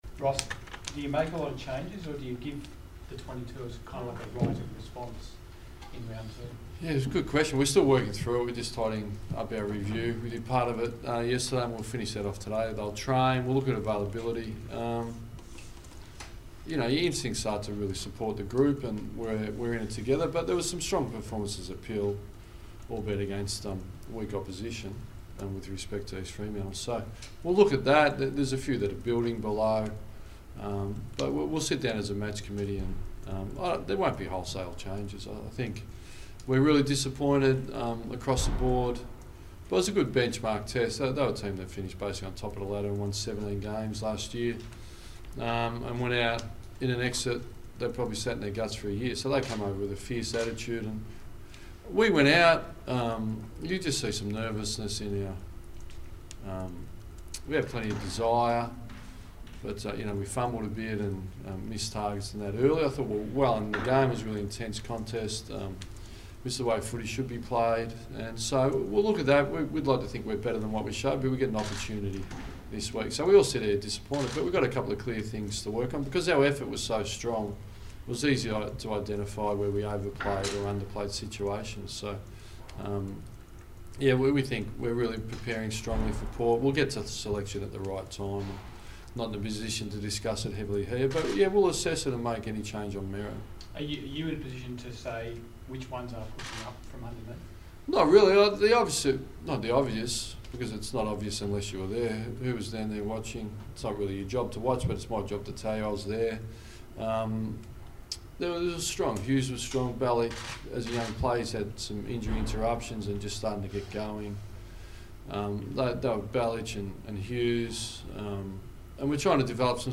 Ross Lyon media conference - 28 March 2017
Ross Lyon spoke to the media ahead of the clash with Port Adelaide.